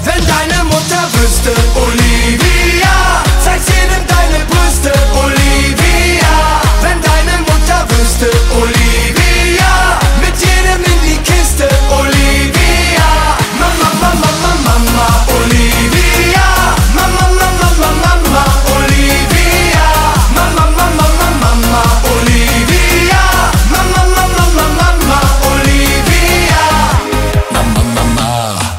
Gattung: Für Blasorchester mit Gesang
Besetzung: Blasorchester